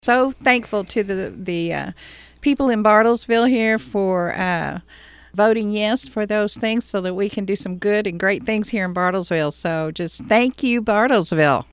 Ward 4 City Councilor Billie Roane stopped by the Bartlesville Radio studios